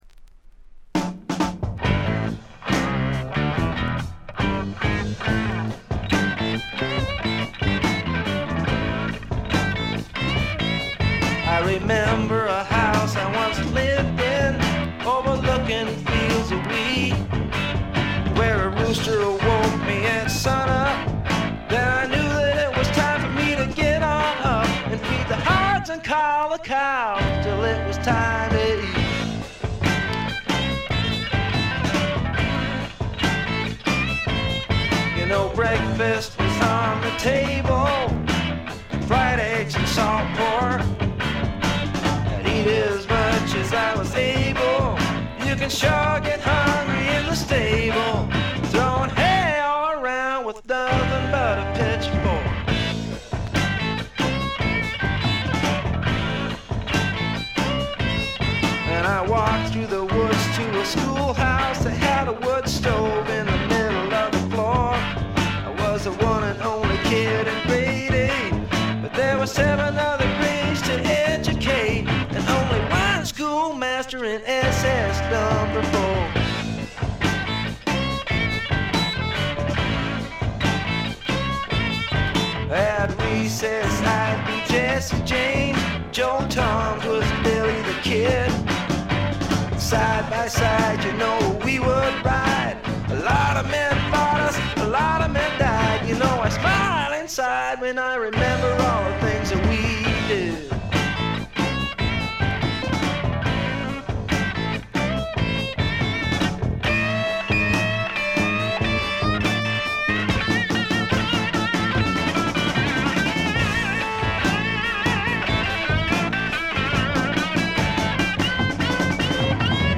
静音部での軽微なチリプチ程度。
試聴曲は現品からの取り込み音源です。